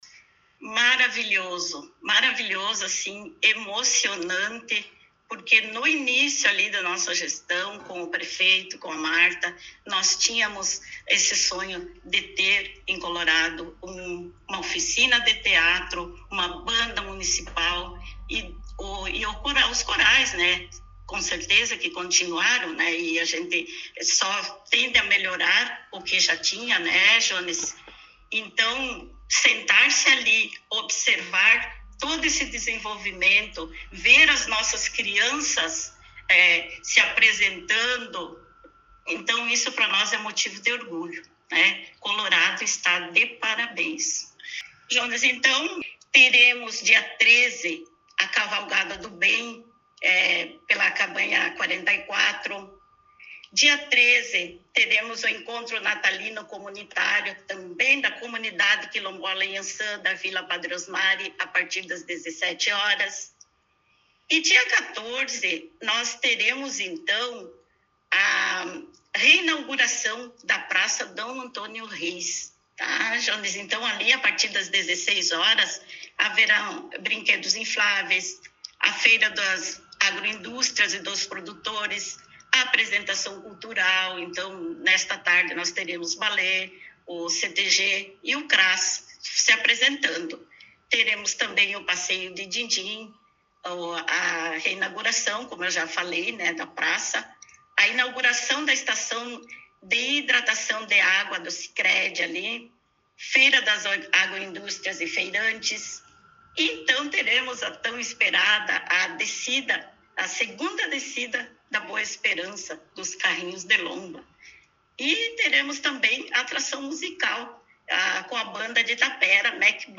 Secretária Municipal de Educação, Jorgina de Quadros, concedeu entrevista